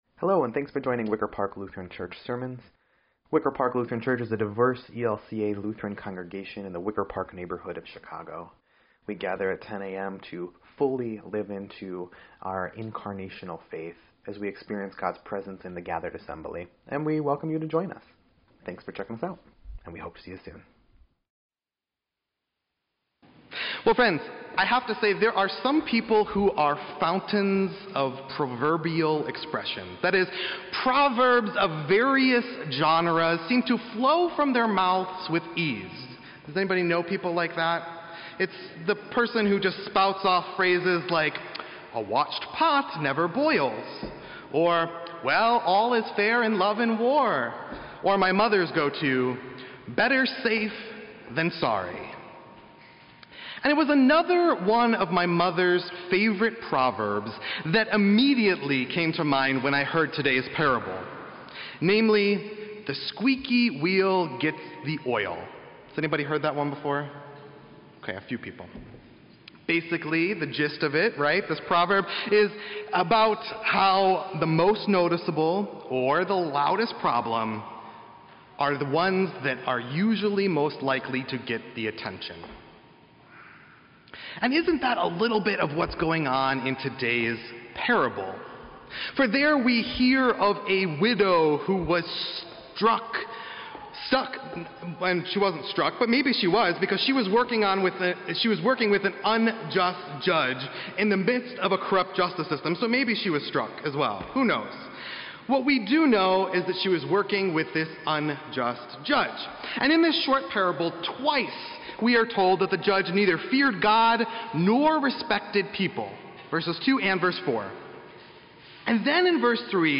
Nineteenth Sunday after Pentecost